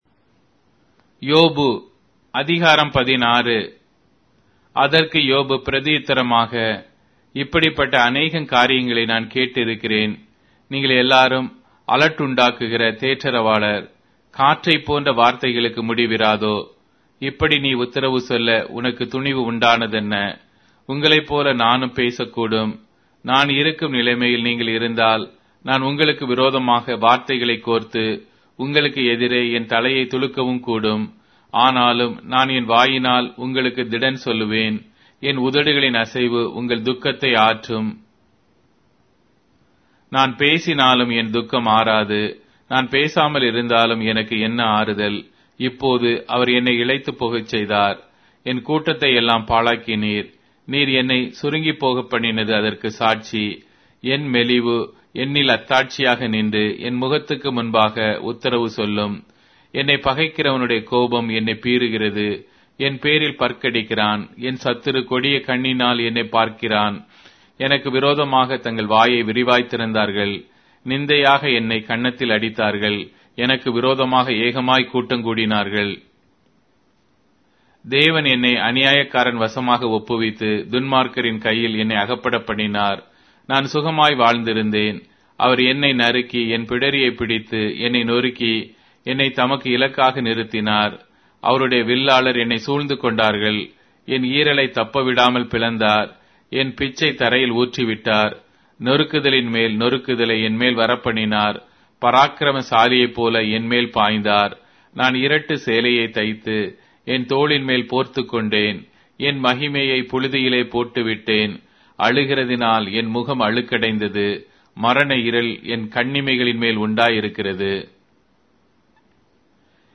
Tamil Audio Bible - Job 9 in Ocvbn bible version